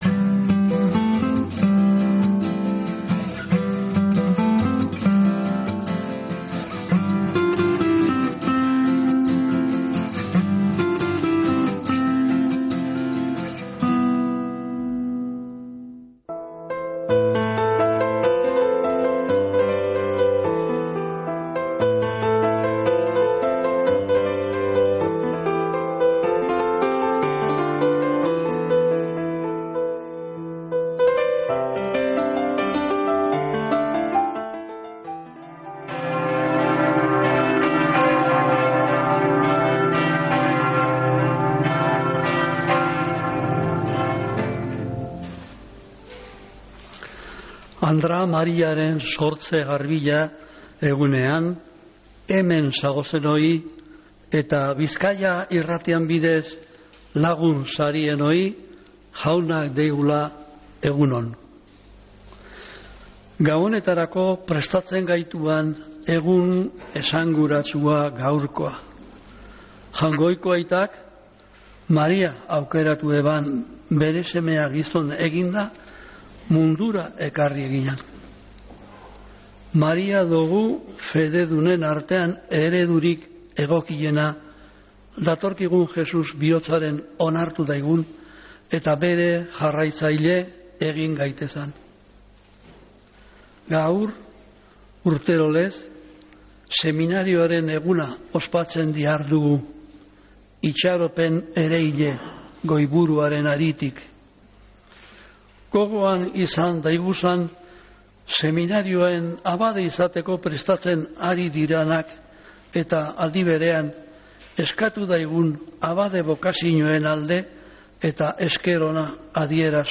Mezea San Felicisimotik | Bizkaia Irratia
Mezea Ama Birjina Sortzez Garbiaren egunez